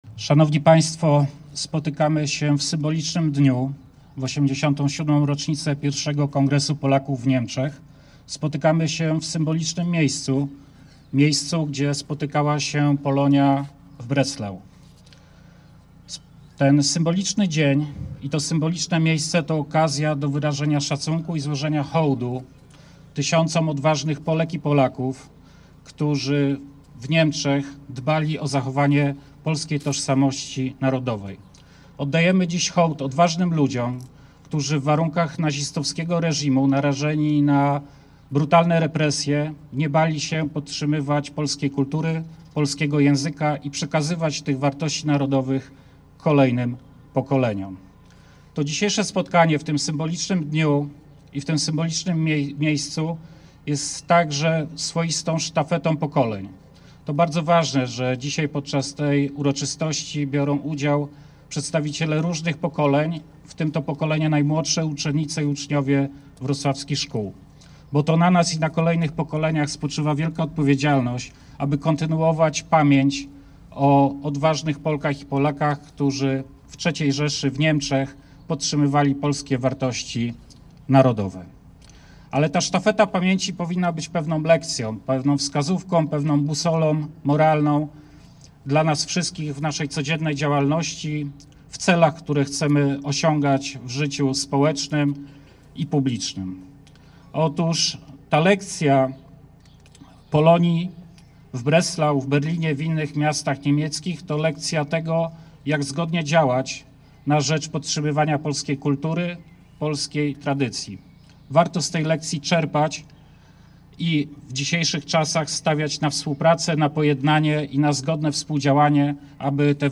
Uroczystości miały miejsce przy kościele św. Marcina na Ostrowie Tumskim we Wrocławiu.
W czasie uroczystości głos zabrali:
Michał Syska – Zastępca Szefa Urzędu do Spraw Kombatantów i Osób Represjonowanych